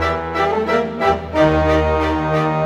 Rock-Pop 07 Orchestra 03.wav